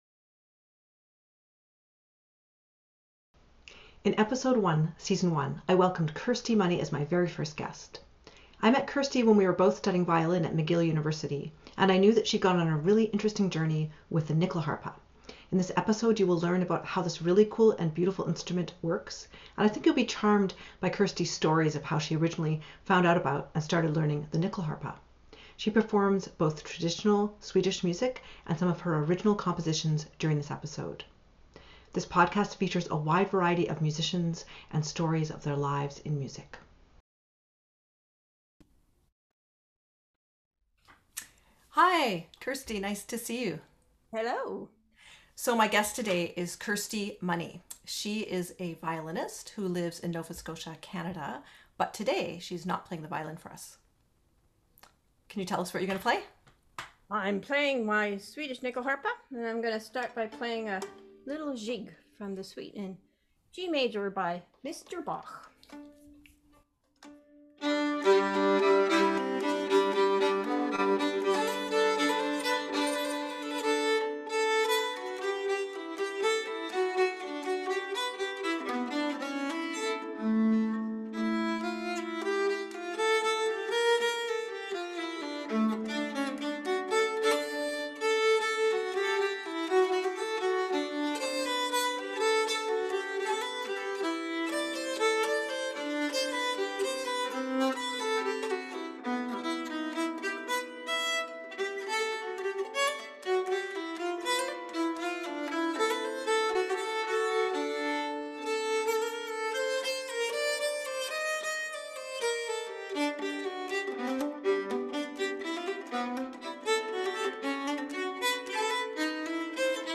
She plays some Bach, a traditional Swedish tune, and one of her own compositions, The Grind Polska. As a separate bonus, at the end of this video you can hear our improv together.